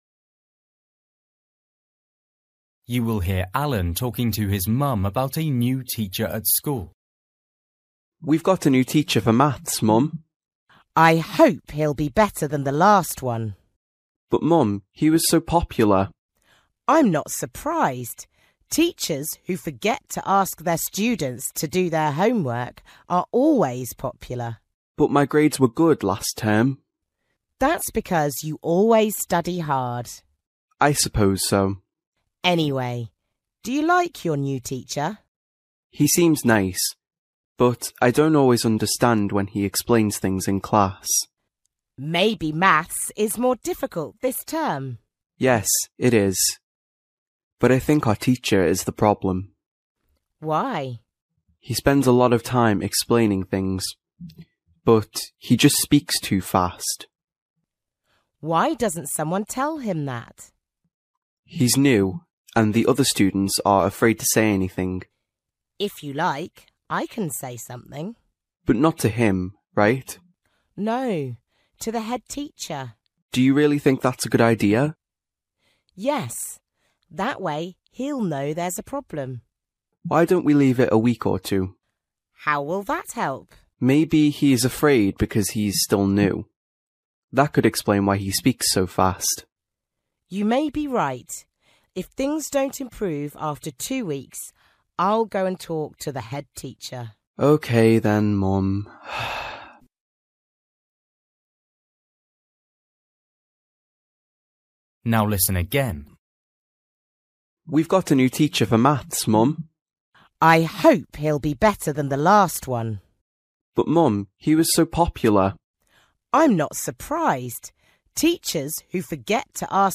You will hear Alan talking to his mum about a new teacher at school.